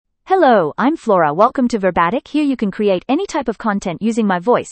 FloraFemale English AI voice
Flora is a female AI voice for English (Australia).
Voice sample
Listen to Flora's female English voice.
Flora delivers clear pronunciation with authentic Australia English intonation, making your content sound professionally produced.